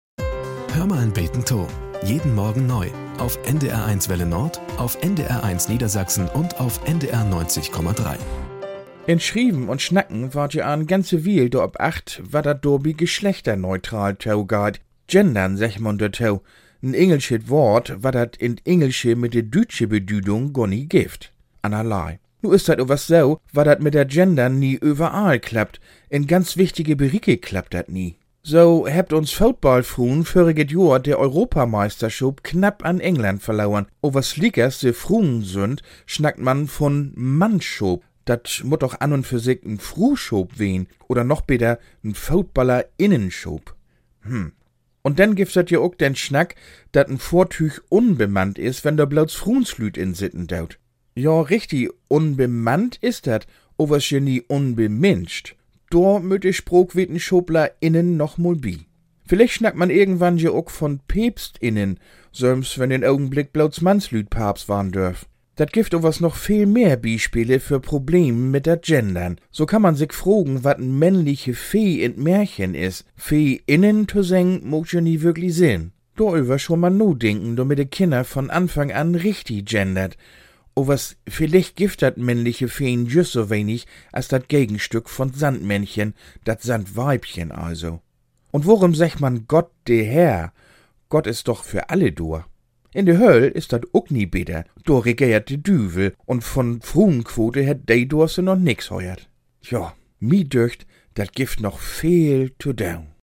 Nachrichten - 27.08.2023